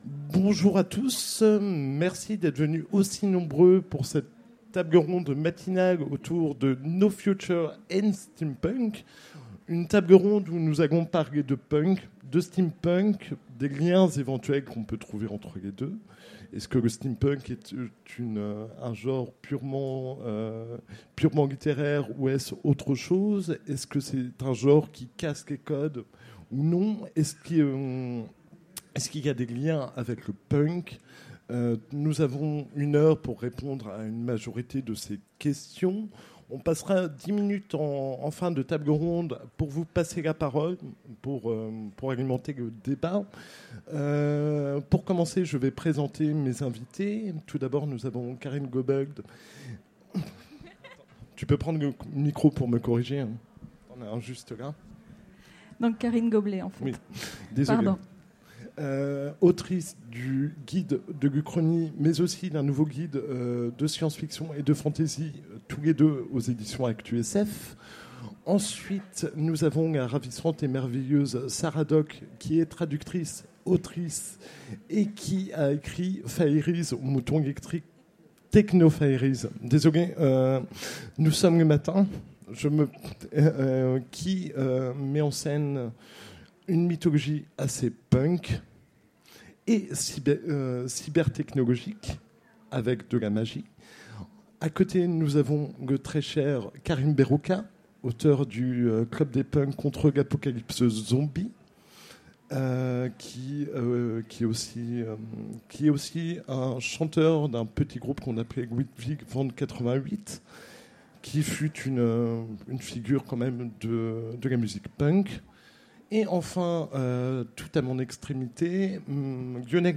Utopiales 2017 : Conférence No future & steampunk